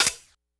Perc_122.wav